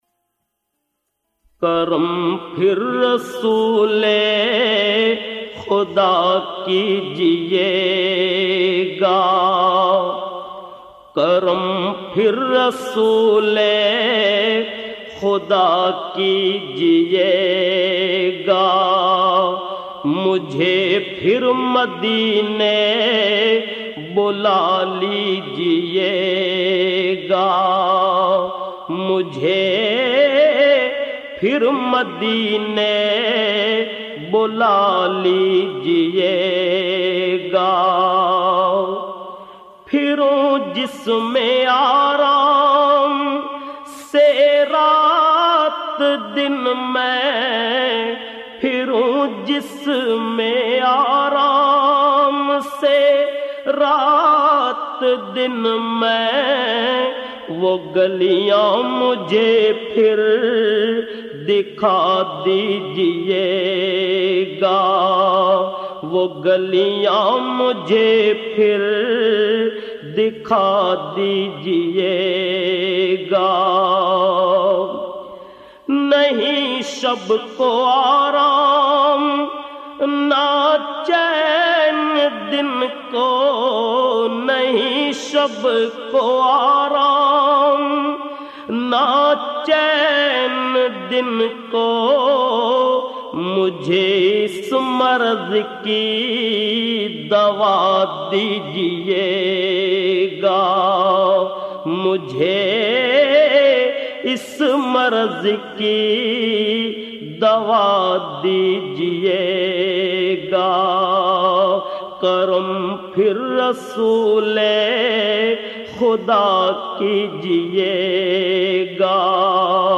نعت رسول مقبول صلٰی اللہ علیہ وآلہ وسلم